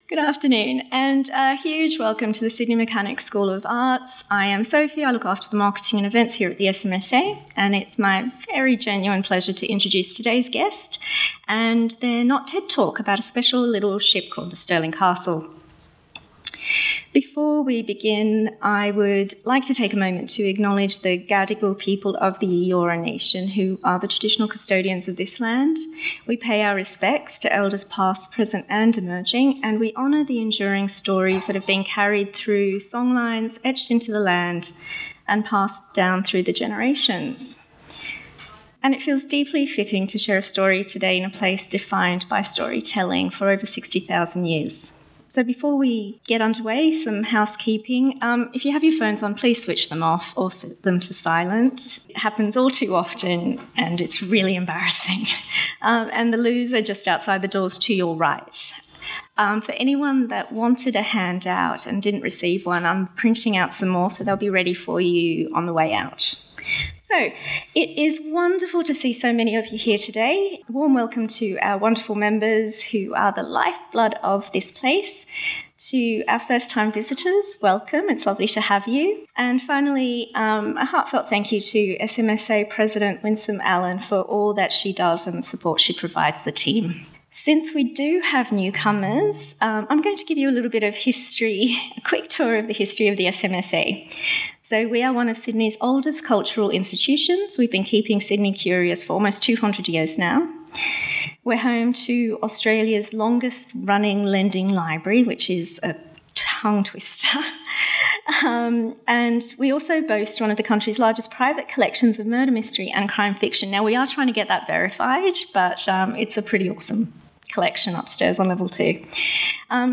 What an unforgettable talk!